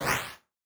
03_Claw_03.wav